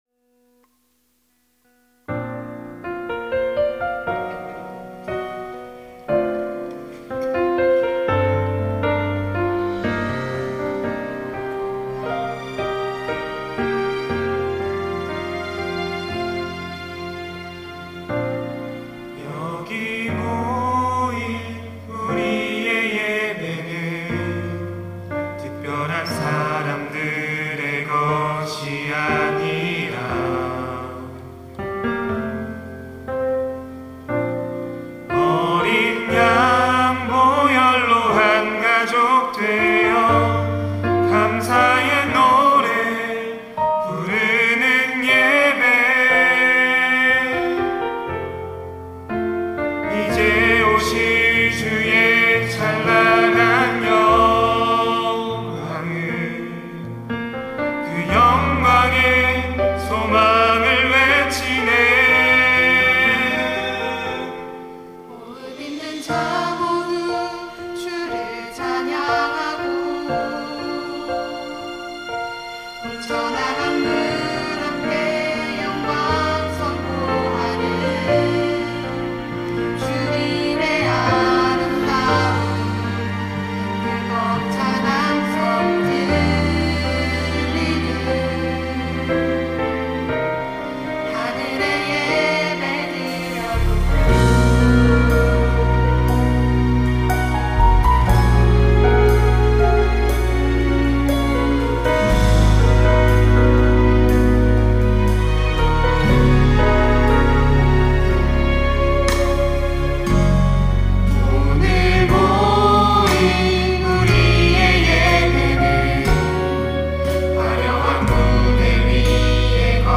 특송과 특주 - 우리의 예배는
청년부 22기